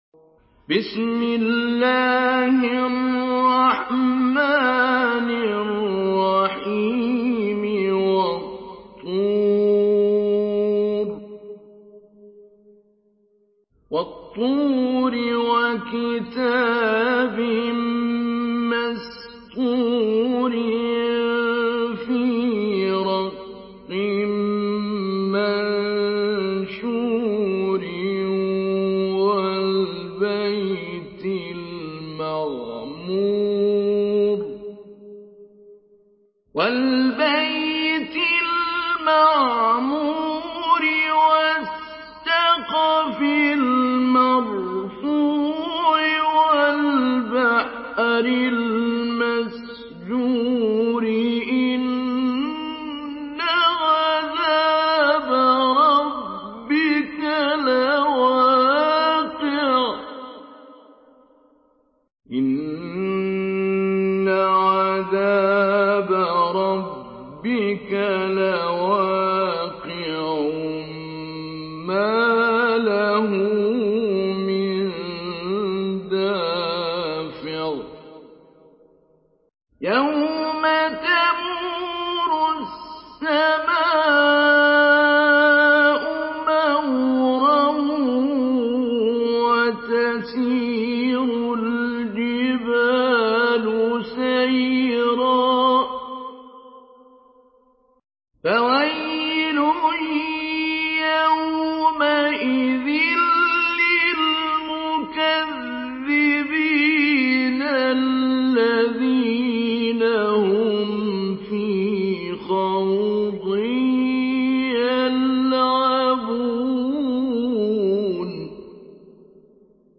Surah At-Tur MP3 in the Voice of Muhammad Siddiq Minshawi Mujawwad in Hafs Narration
Surah At-Tur MP3 by Muhammad Siddiq Minshawi Mujawwad in Hafs An Asim narration.